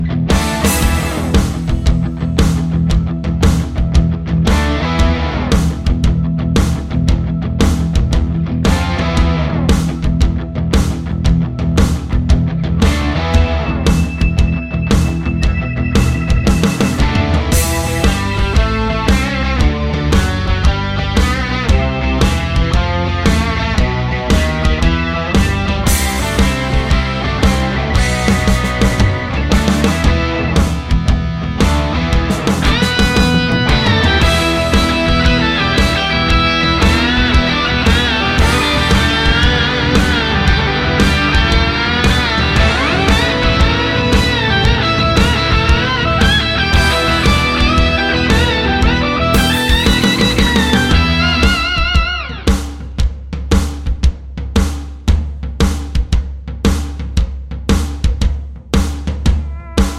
Clean Backing Vocals Musicals 3:13 Buy £1.50